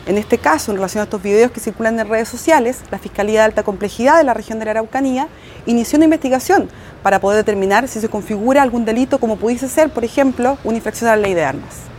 Junto al hombre, que a sus espaldas tiene vegetación, se advierten un hacha y un machete, pese a que en sus dichos asevera que la supuesta agrupación cuenta con armas muy similares a las que usan quienes cometen hechos de violencia en la zona.